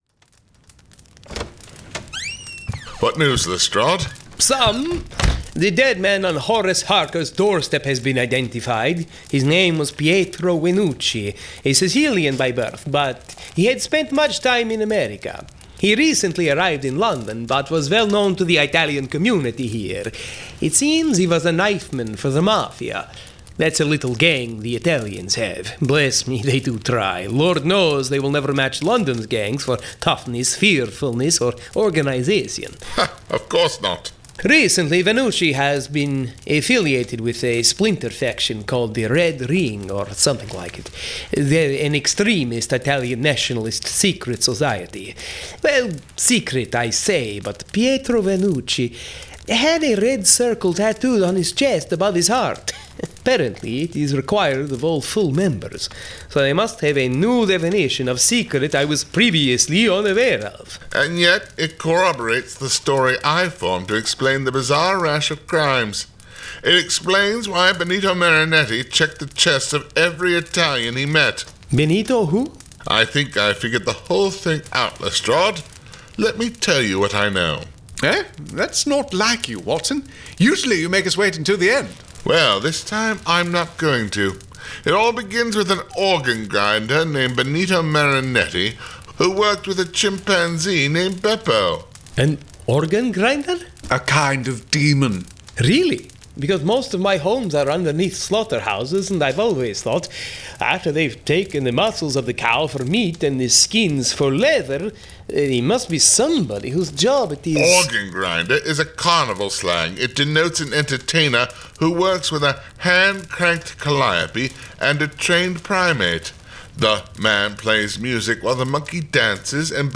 Full Cast. Cinematic Music. Sound Effects.
[Dramatized Adaptation]
Genre: Mystery